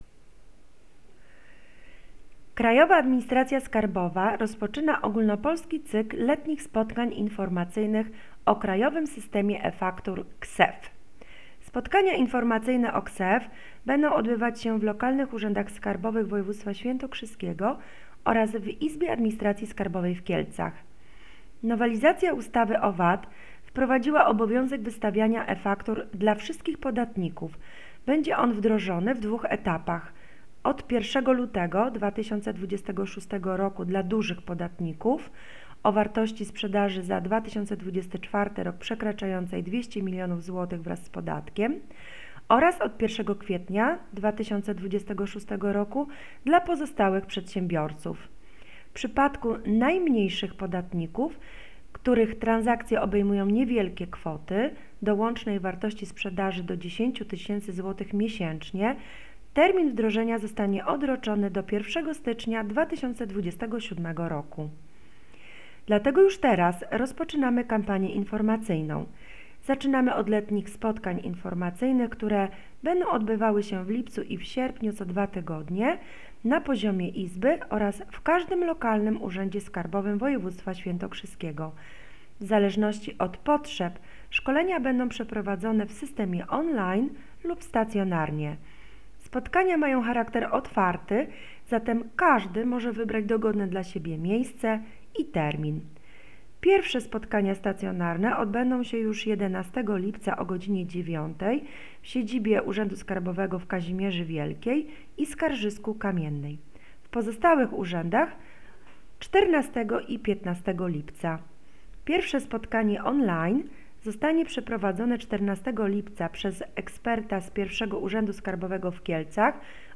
Nagranie głosowe komunikatu dot. spotkań informacyjnych o KSeF